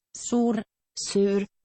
Prono guide for Samska sur